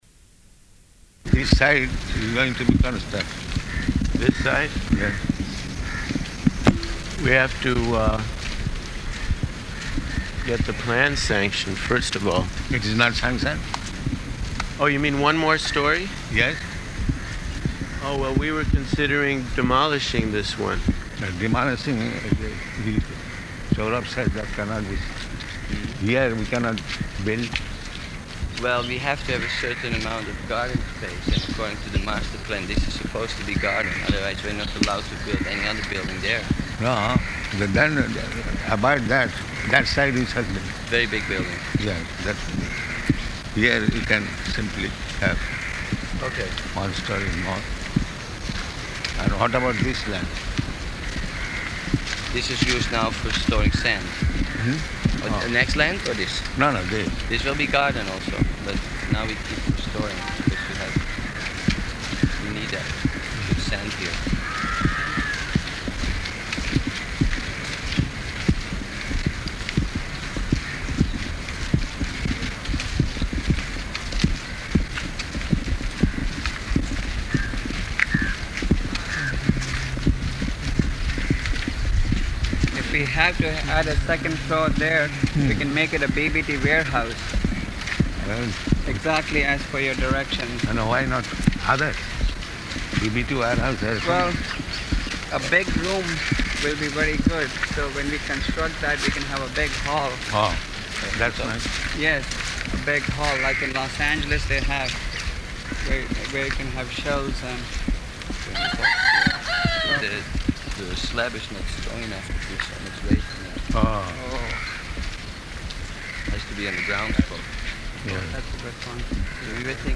Morning Walk [partially recorded]
Type: Walk
Location: Bombay